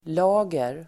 Uttal: [l'a:ger]